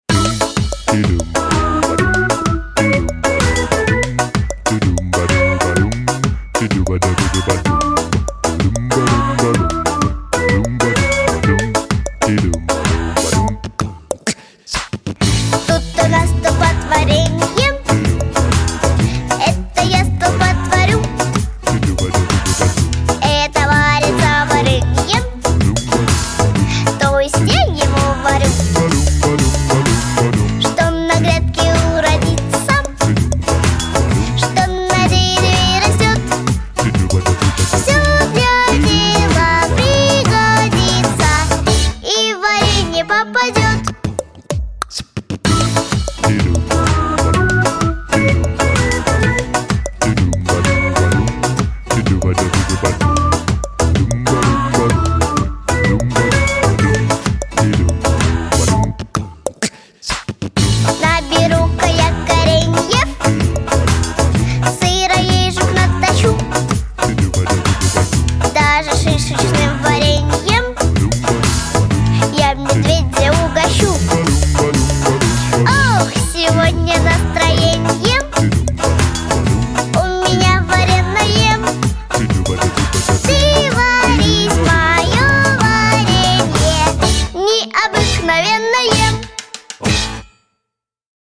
Жанр: "Acapella"